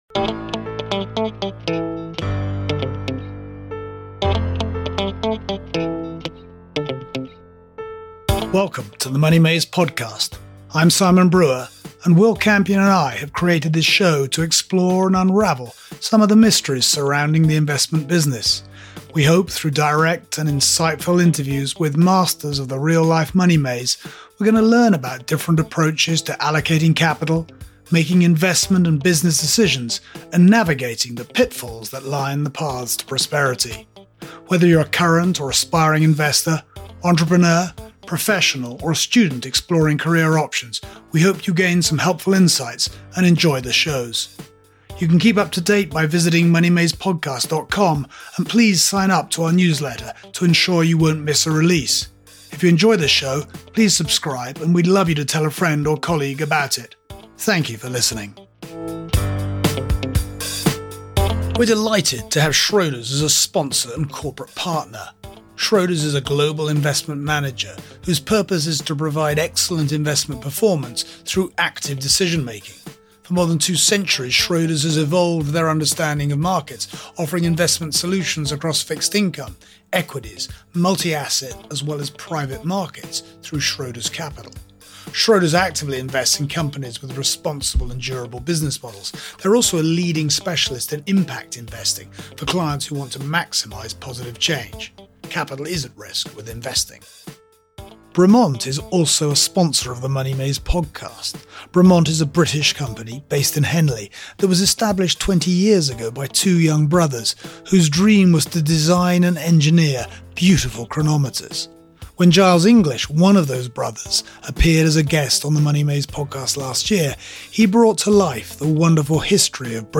In this miniseries we are interviewing a group of the largest institutional investors (the ‘super allocators’), to understand how these immense pools of capital think about the assets they invest in, shifting asset allocations, passive versus active, public versus private, and...